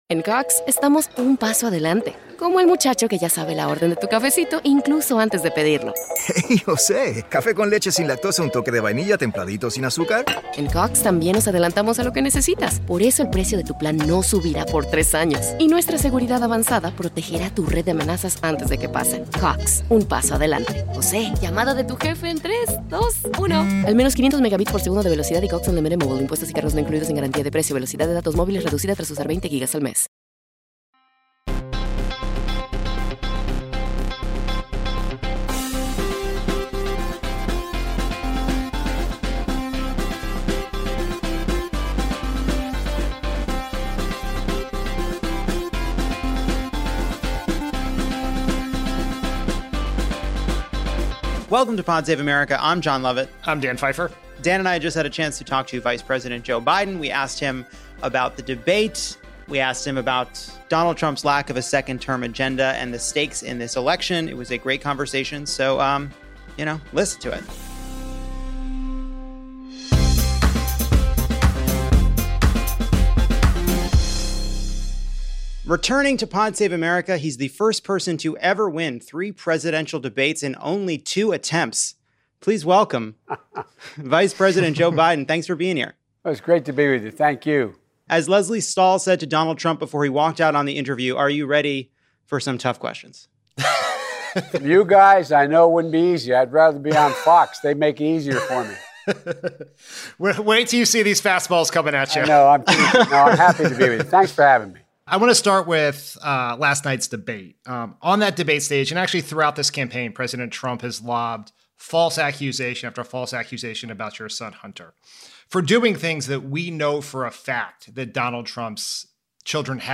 Dan and Jon L. talk with Democratic presidential nominee Joe Biden about what’s at stake for our democracy in the 2020 election, his final debate with Donald Trump, his plans for tackling the climate crisis, Vote Early Day, and what everyone can do to help in the final days of the campaign.